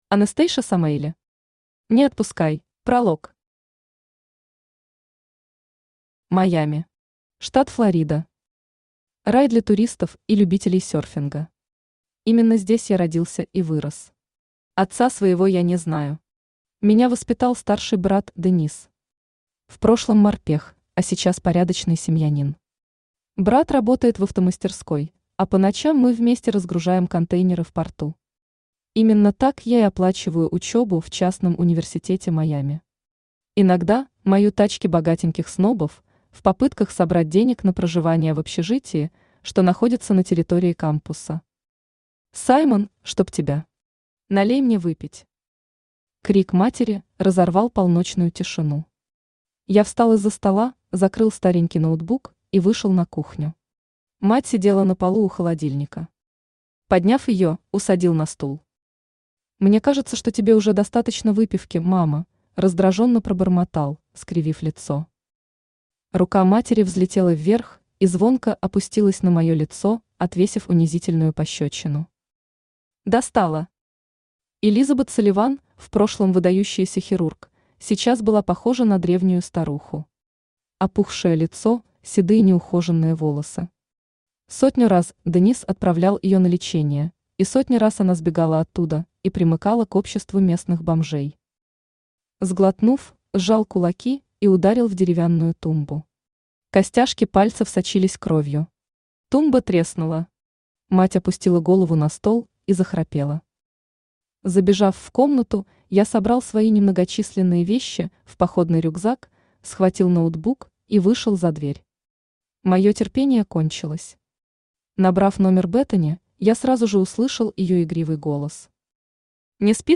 Аудиокнига Не отпускай | Библиотека аудиокниг
Aудиокнига Не отпускай Автор Anastasia Avi Samaeli Читает аудиокнигу Авточтец ЛитРес.